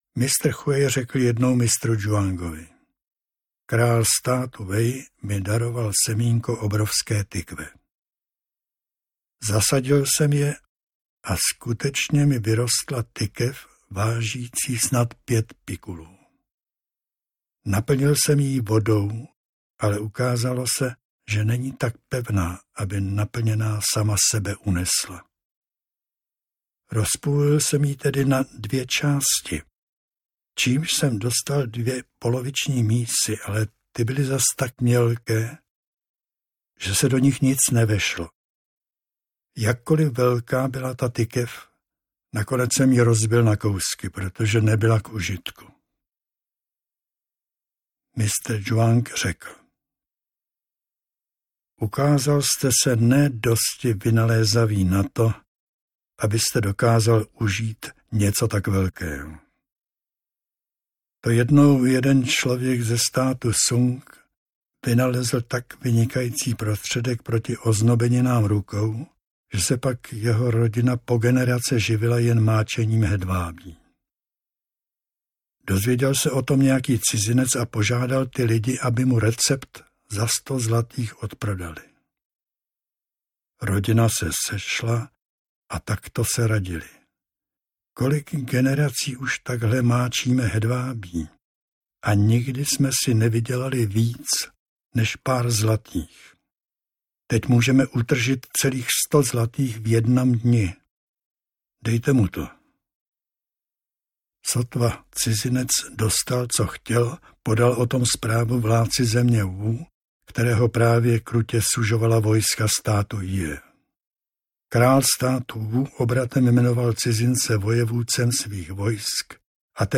V této audioknize čte Oldřich Král vybrané kapitoly ze svého překladu Sebraných spisů Mistra Zhuanga.
Ukázka z knihy